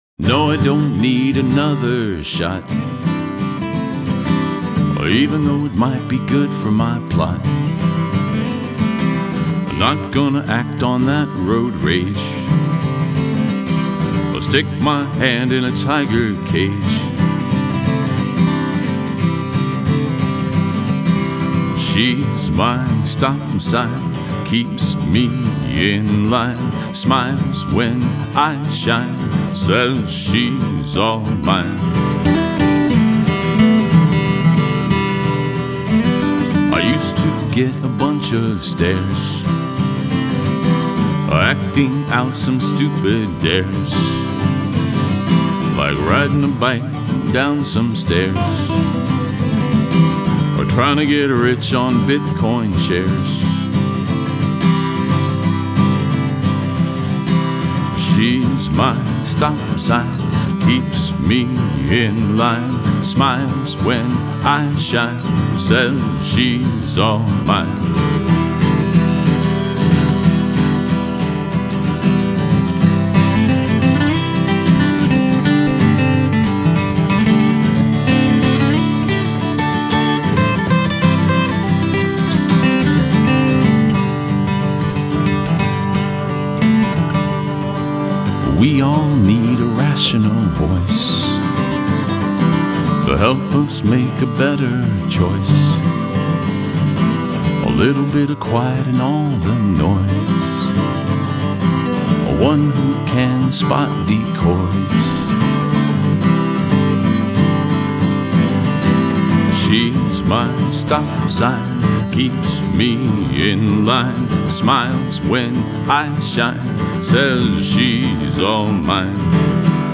Americana folk album